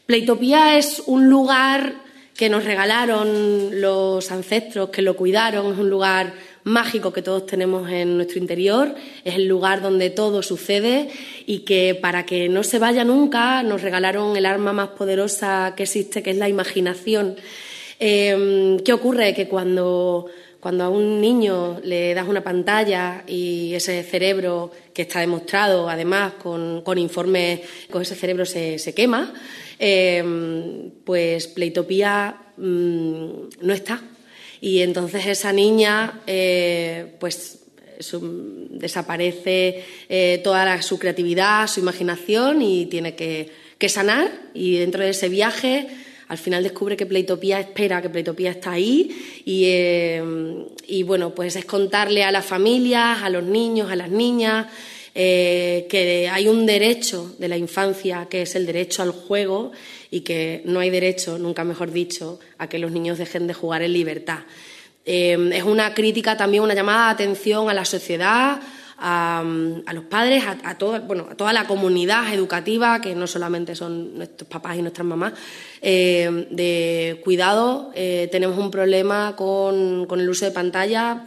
han presentado en rueda de prensa el proyecto Playtopía o el derecho jugar”. Se trata de una iniciativa que combina teatro, música, humor y audiovisuales para abordar la dependencia tecnológica en la infancia y la necesidad de recuperar el juego como espacio de crecimiento y libertad.